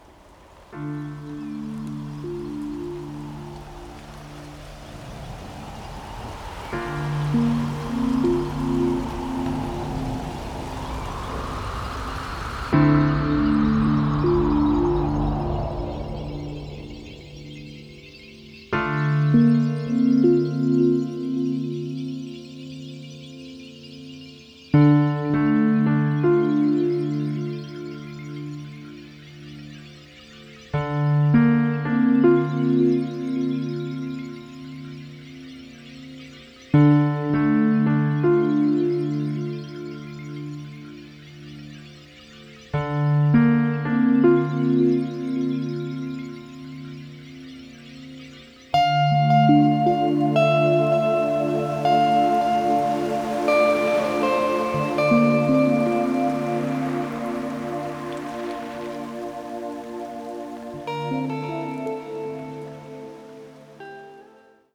wohltuenden Kompositionen
Qualität inneren Friedens